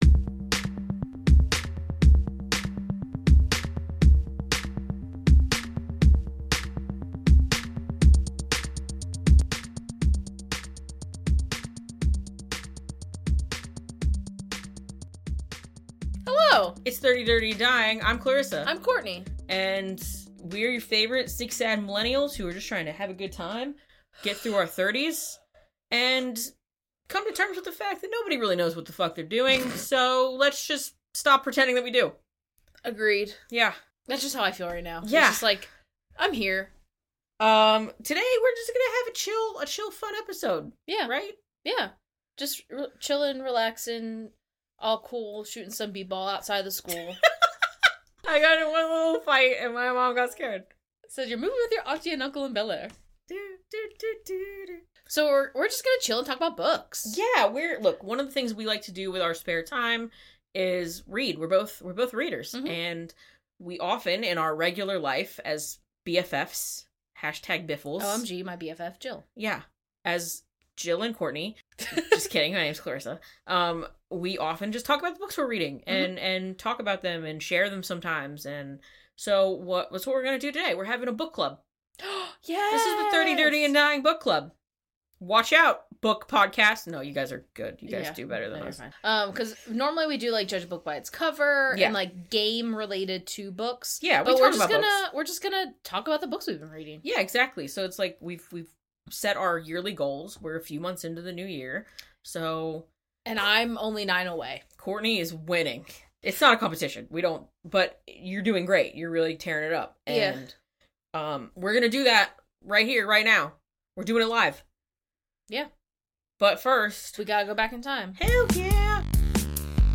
It’s time for book club — 30, Dirty & Dying edition. The season of favorite things continues with a chat about your cohosts’ favorite pastime.